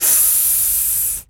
Animal_Impersonations
snake_hiss_02.wav